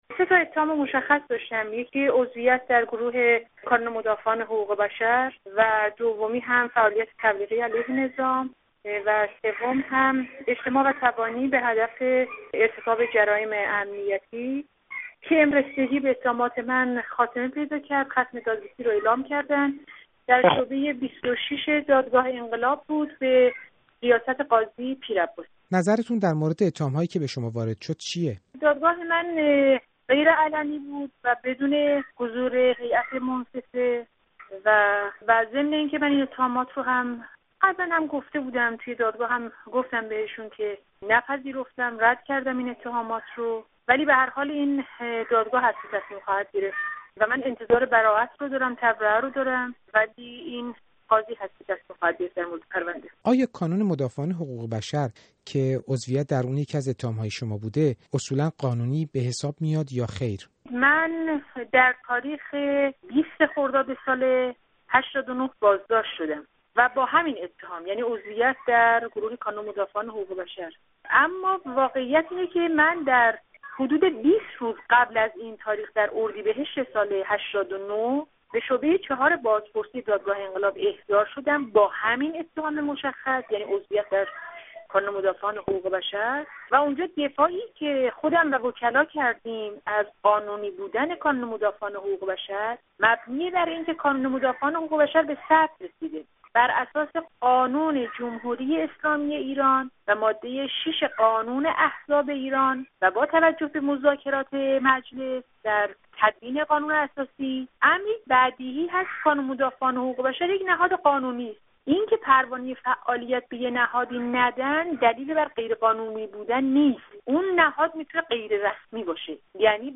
گفت و گو با نرگس محمدی، فعال مدنی، درباره دومین جلسه محاکمه وی